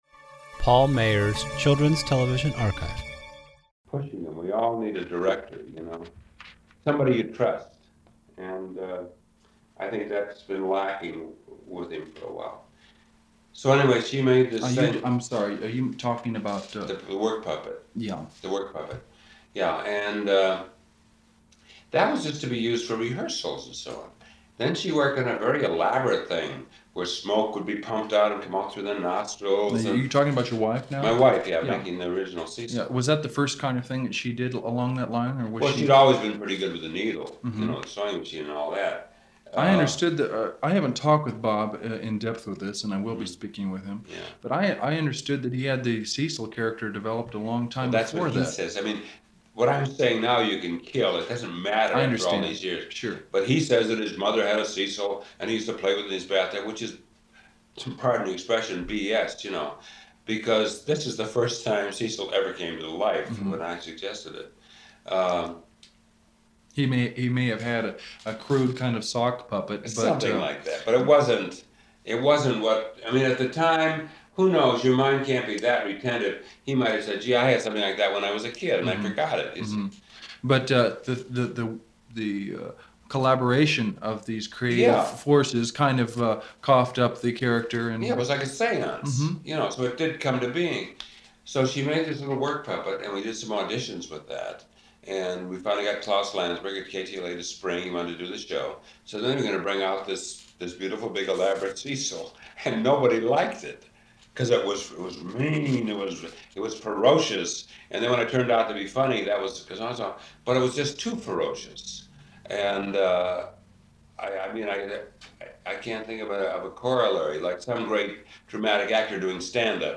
Daws Butler Interviews